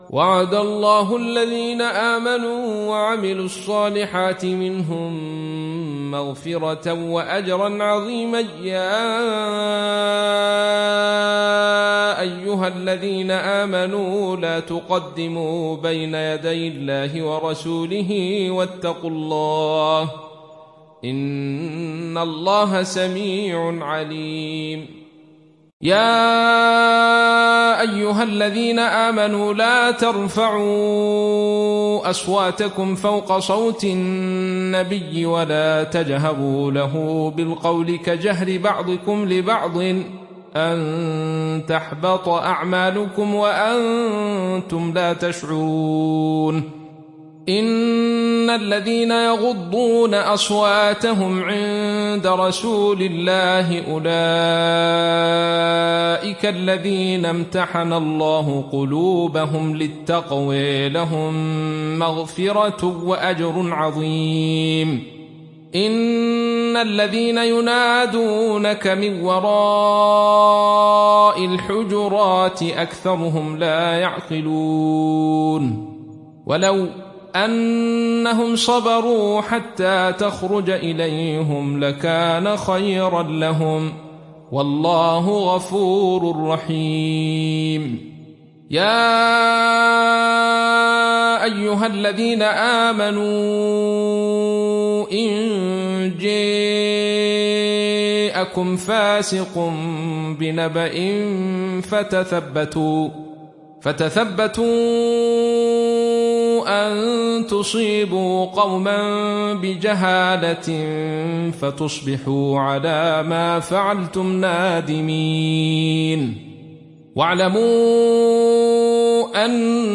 دانلود سوره الحجرات mp3 عبد الرشيد صوفي روایت خلف از حمزة, قرآن را دانلود کنید و گوش کن mp3 ، لینک مستقیم کامل